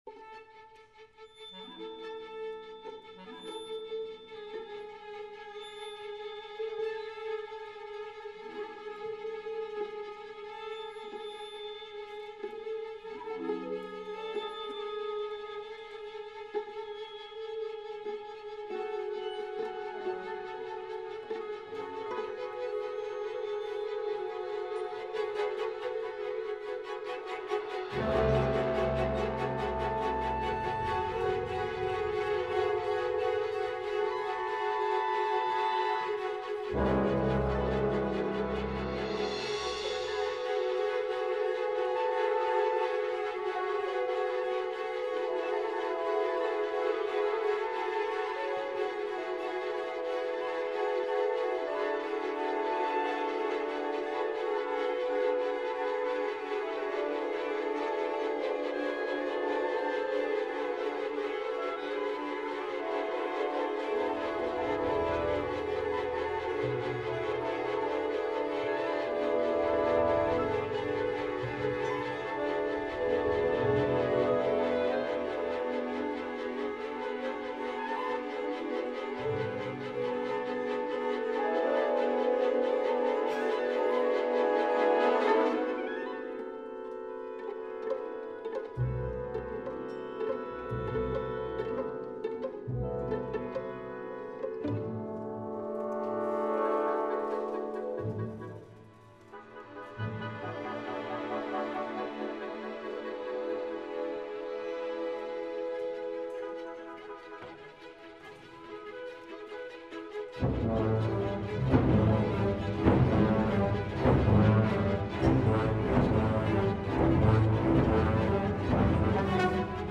Cycles Unspoken (1995) orchestra
The work is in four movements, played without pause. The first movement, the largest section of the work, opens with an unceasing rhythmic pulse on a single pitch. The pitch becomes surrounded by mostly diatonic pitches, changing into cross-rhythms, notably two beats over three beats. The clusters of notes evolve into simple repeated melodic patterns. The rhythms and harmonies gradually grow more complex as this movement drives to its climax.
A dense texture is created by gradually adding layers. The texture eventually rises up and thins out to one note, leaving behind the opening pulse for the fourth movement.
A simple cyclic string chorale of four chords subtly accelerates and decelerates over the half-step rocking of the low strings. A gentle melody barely emerges in the english horn. The chorale then softly rises leaving only a simple chord.